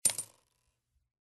На этой странице собраны звуки копилки: от звонкого падения монет до глухого стука накопленных сбережений.
Звон монетки упавшей в копилку с деньгами